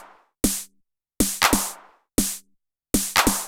SD + CLP  -R.wav